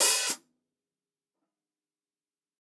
TUNA_OPEN HH_3.wav